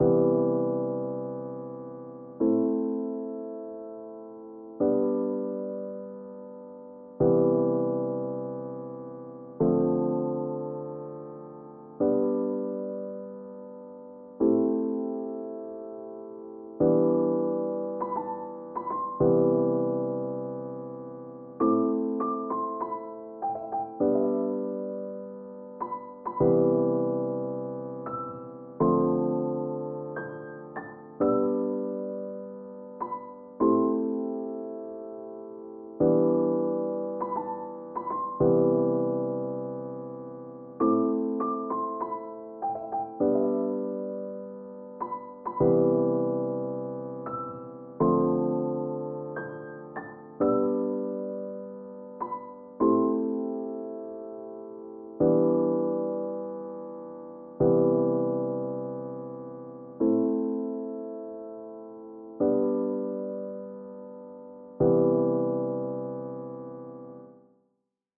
sentimental!!